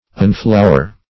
Search Result for " unflower" : The Collaborative International Dictionary of English v.0.48: Unflower \Un*flow"er\, v. t. [1st pref. un- + flower.] To strip of flowers.